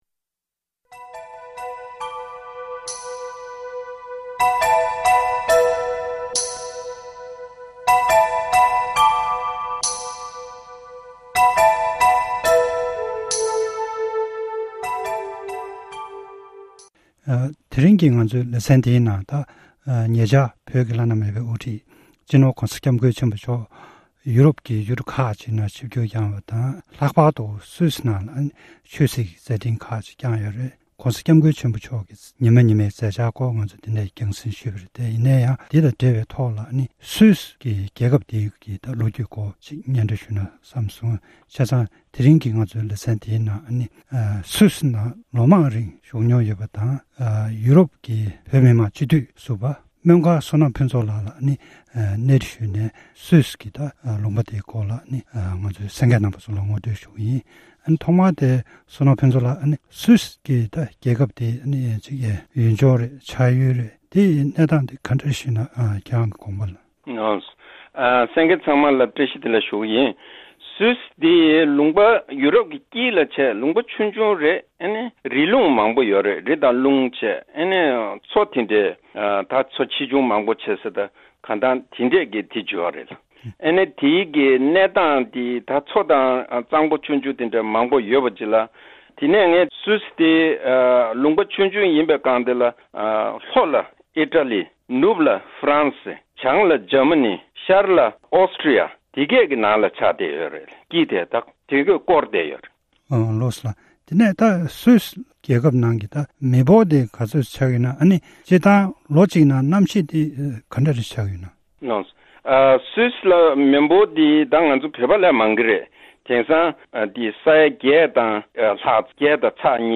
གནས་འདྲི་ཞུས་པ་ཞིག་གཤམ་ལ་གསན་རོགས་གནང་།།